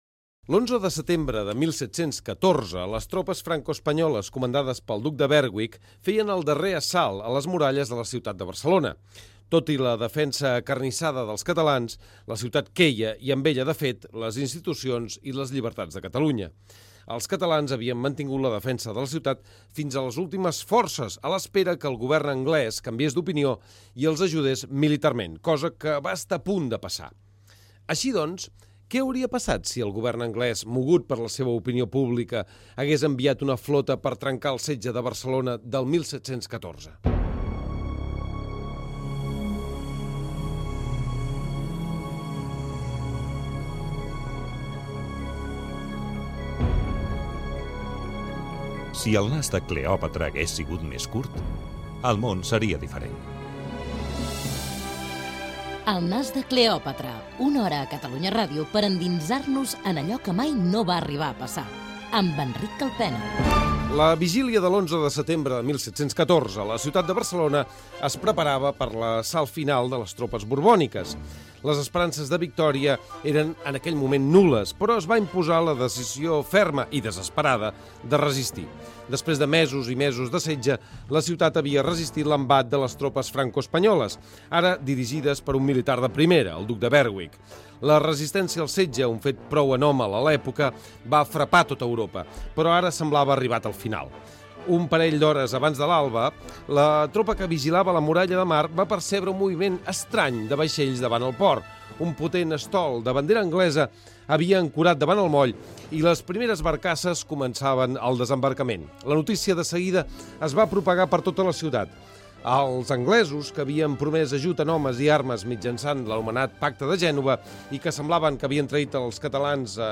La caiguda de la ciutat de Barcelona l'any 1714, indicatiu del programa
Gènere radiofònic Divulgació